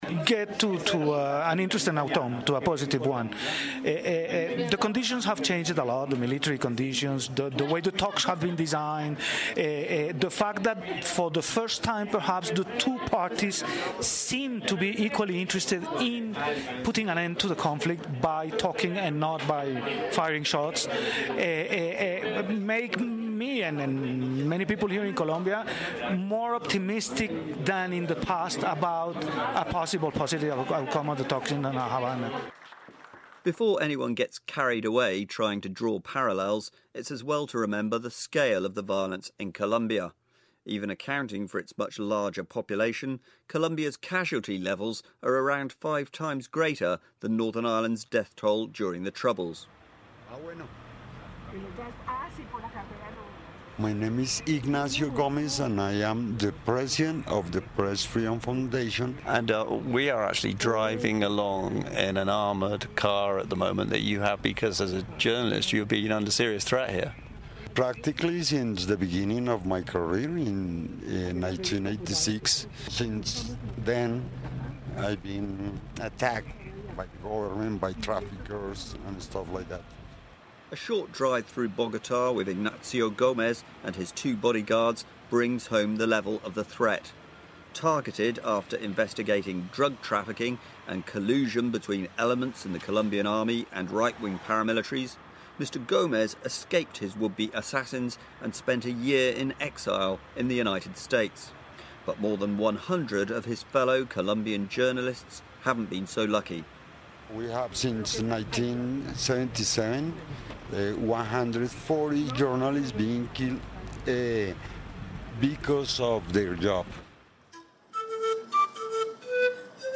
2nd part of BBC Good Morning Ulster Report on NI and Colombia